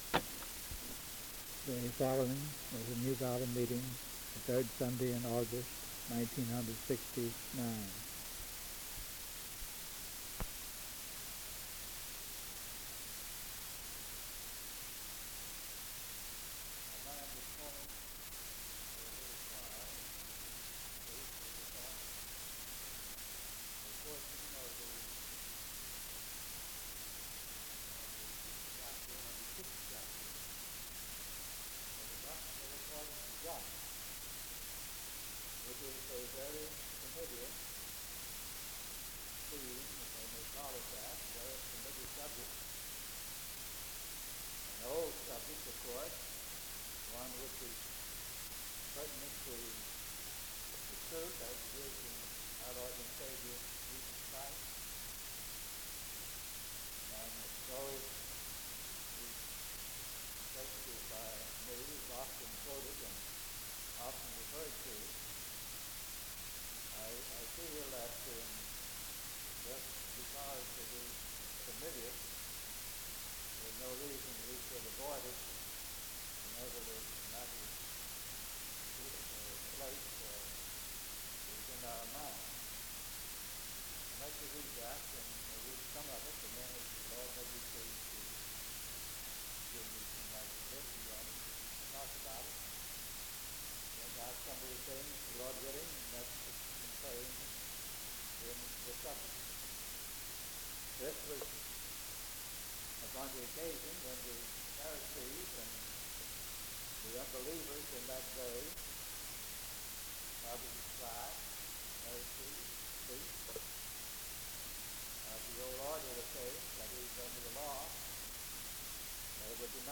Primitive Baptists
Location Lucketts (Va.) Loudoun County (Va.)